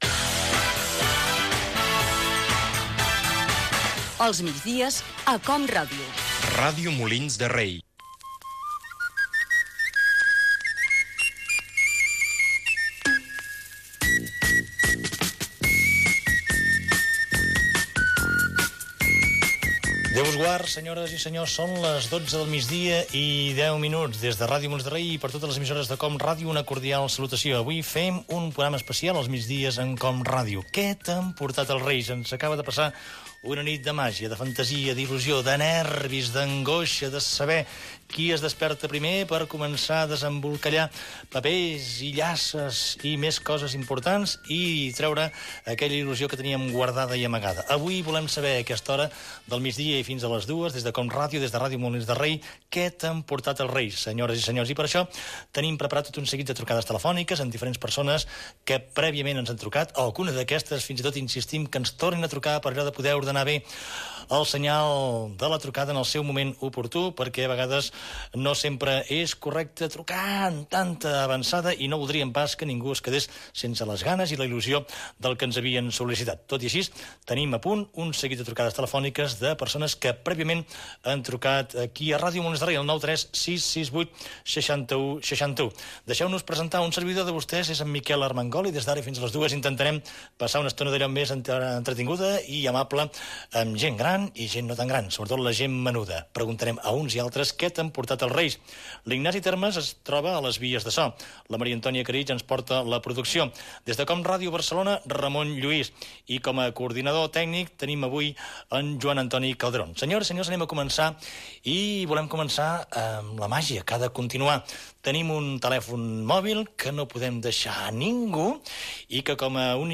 fa2be9a3ff4e7ccdee4df4a926a9bedf0e1053ea.mp3 Títol COM Ràdio Emissora Ràdio Molins de Rei Cadena COM Ràdio Titularitat Pública municipal Nom programa Els migdies amb COM Ràdio Descripció Indicatiu del programa, inici del programa del dia de Reis. Pregunta als oients: què t'han portat els Reis?.
conversa telefònica amb els Reis d'Orient: Melcior, Gaspar i Baltasar